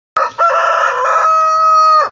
Ayam_Suara.ogg